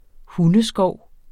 Udtale [ ˈhunə- ]